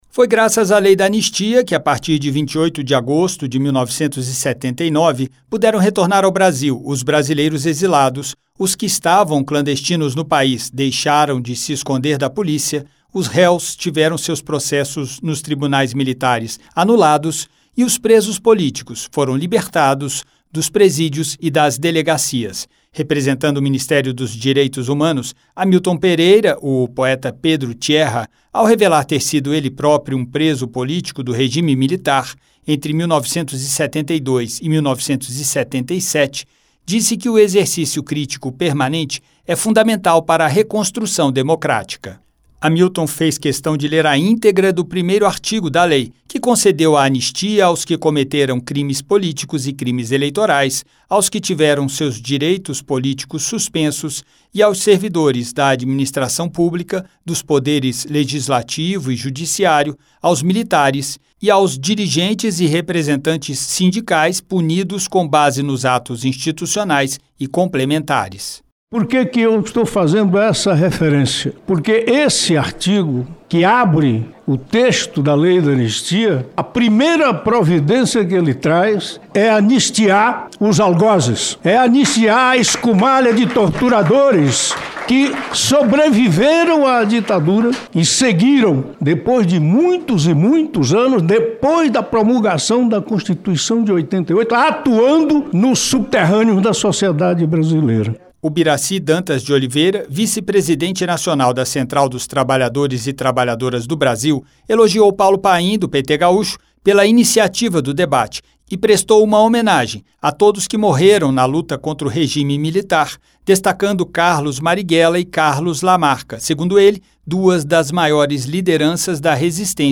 A Comissão de Direitos Humanos (CDH) celebrou nesta terça-feira (27), em audiência pública, os 45 anos da Lei da Anistia (Lei 6.683, de 1979), que em 28 de agosto de 1979 deu início à redemocratização do Brasil.